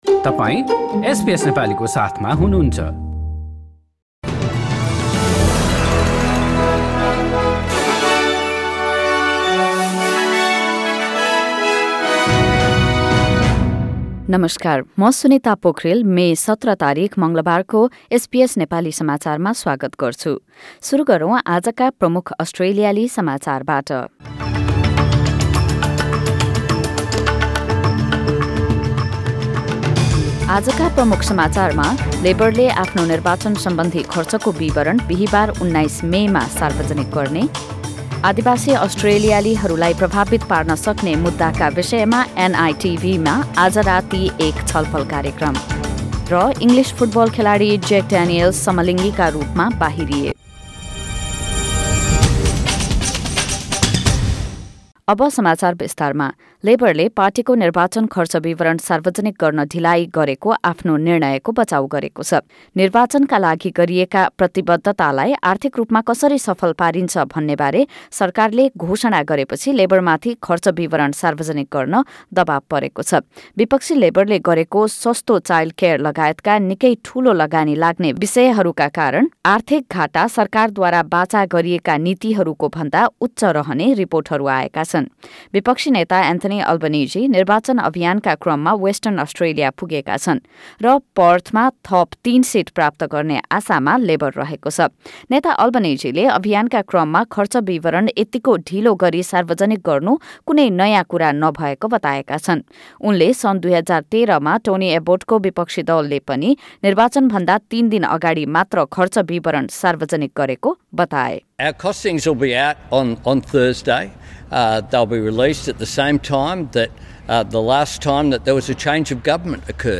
एसबीएस नेपाली अस्ट्रेलिया समाचार: मङ्गलवार १७ मे २०२२